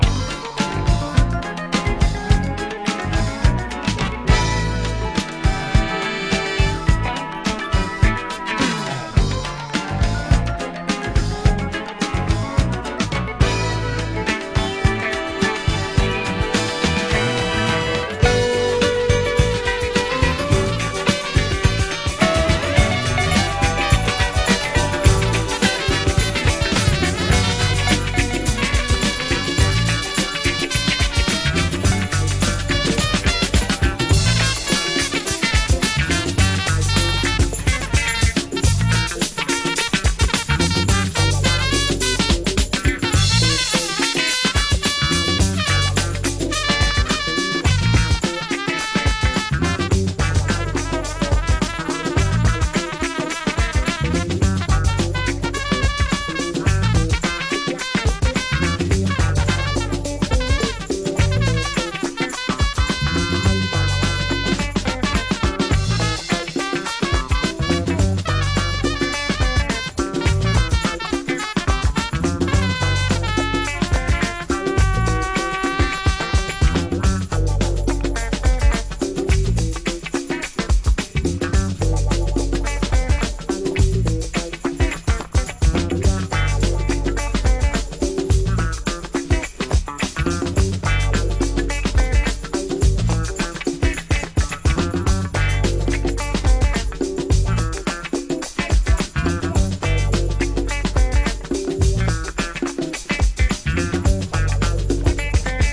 Exclusive mix CD by one of Detroit's finest DJ's...
Disco House Soul